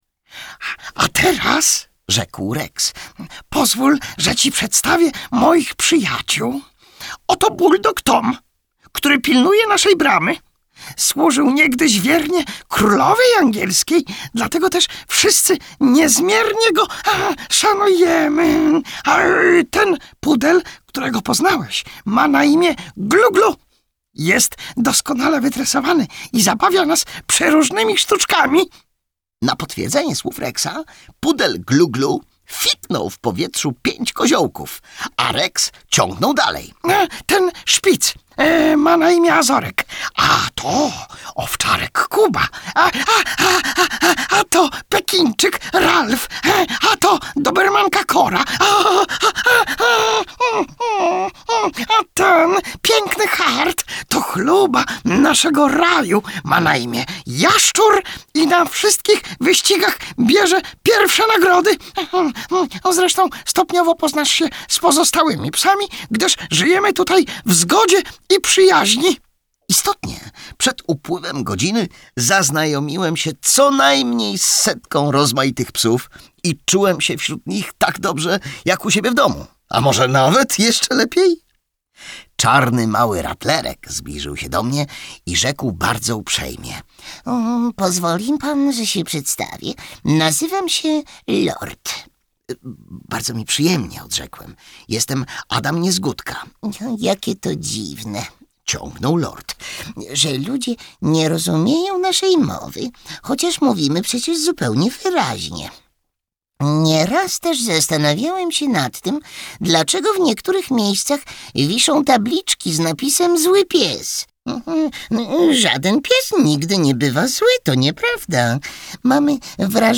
Akademia pana Kleksa - Jan Brzechwa - audiobook + książka